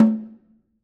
Snare2-HitNS_v5_rr2_Sum.wav